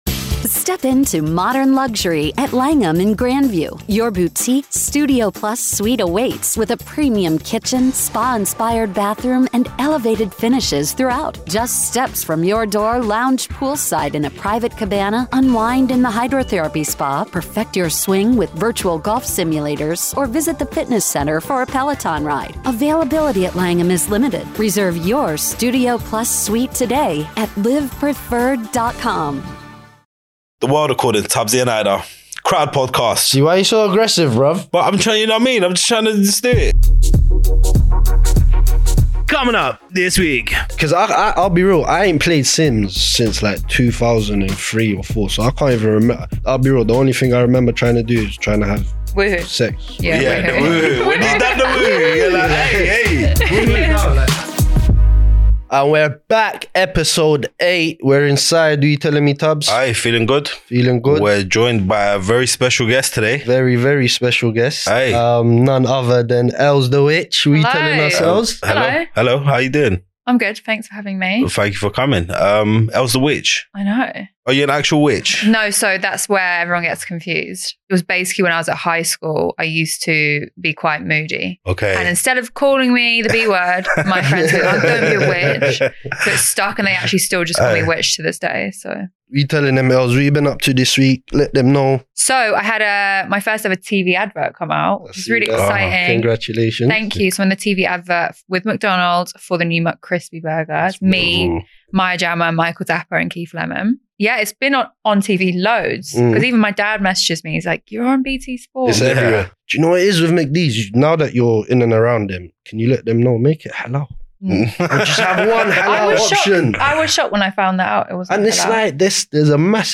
This week the boys are joined by their first gaming YouTuber!